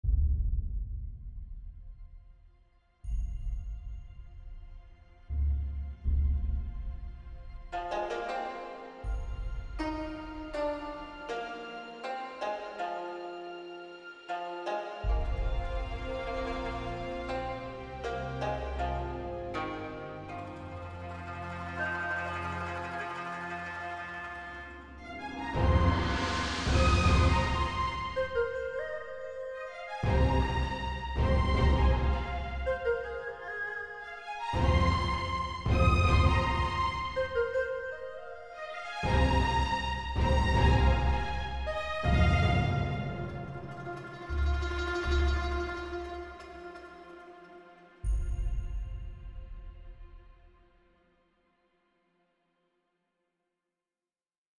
modern east Asian orchestral sound
Then again, just having the guzheng, ehru, and xiao around (and eventually the sheng and pipa once I get them from OT) in your orchestral mix already "flavors" the thing.
music soundtrack gamemusic instrumental backgroundmusic